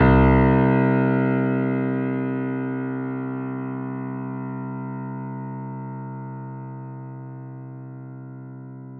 pianoSounds